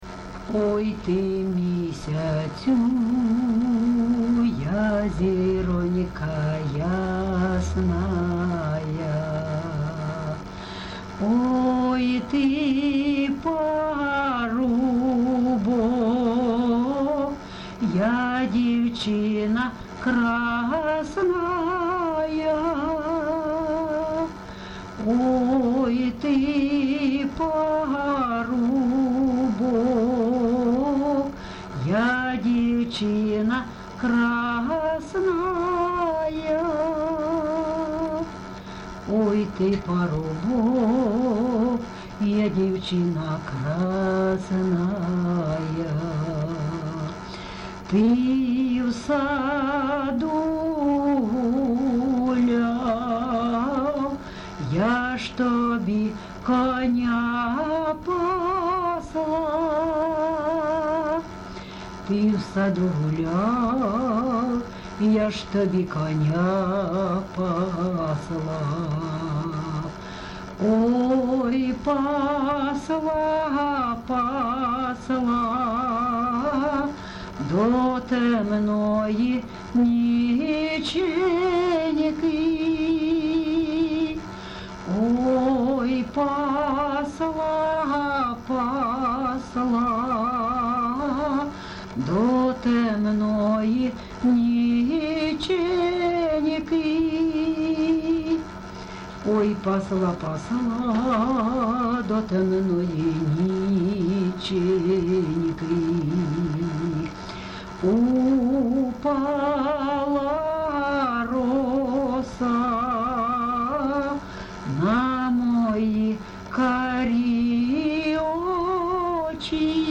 ЖанрПісні з особистого та родинного життя
Місце записус. Лозовівка, Старобільський район, Луганська обл., Україна, Слобожанщина